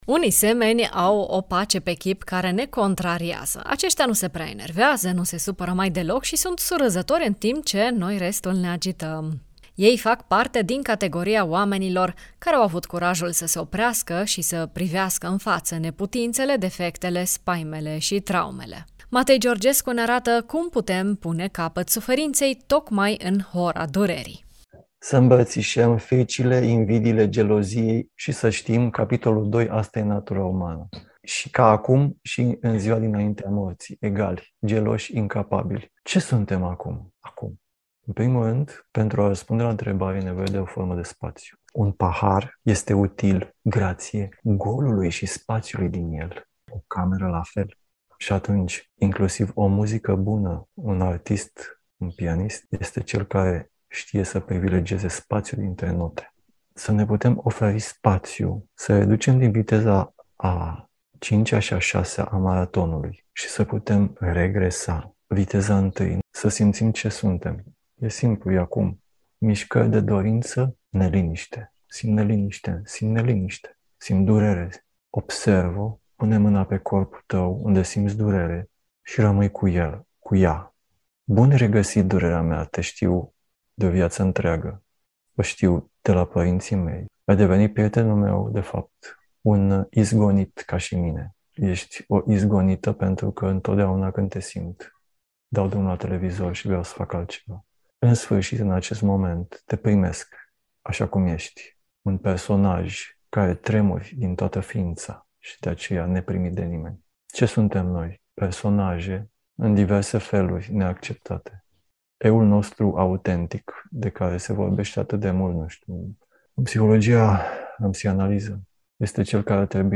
psihoterapeut, psihanalist